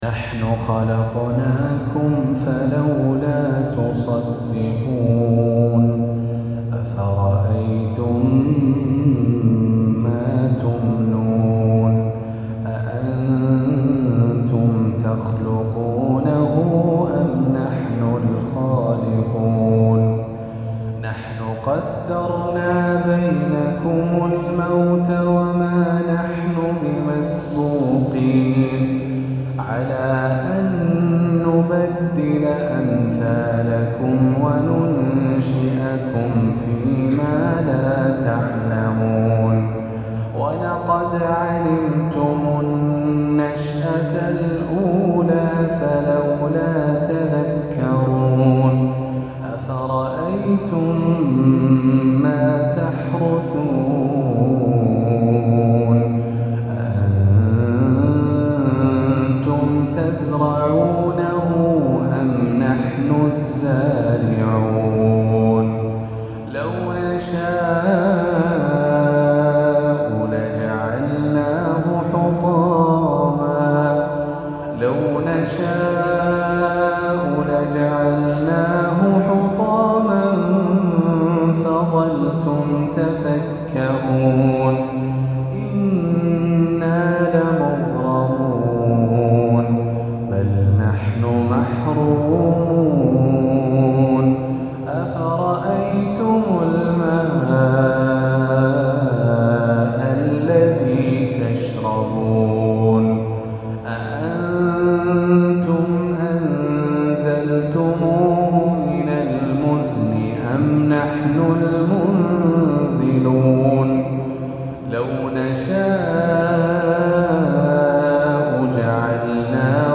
عشائية جميلة